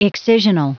Prononciation du mot excisional en anglais (fichier audio)
excisional.wav